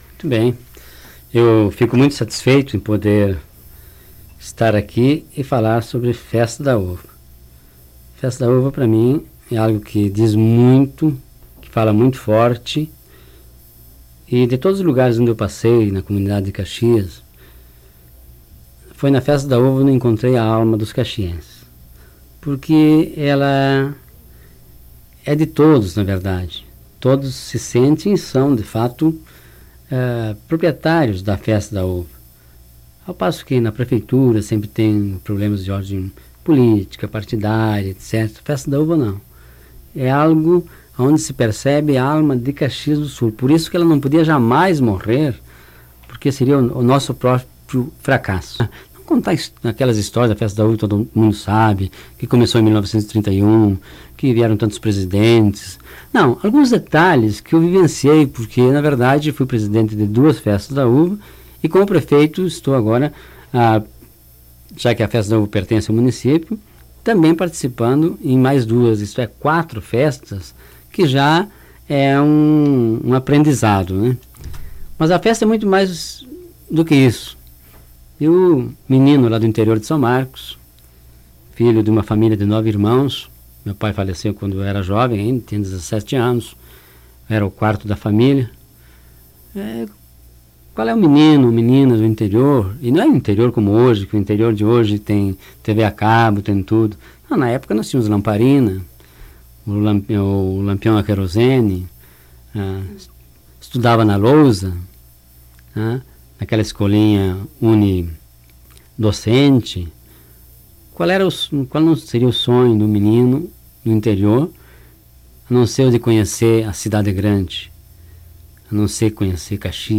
Trecho de áudio da entrevista